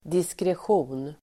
Uttal: [diskresj'o:n]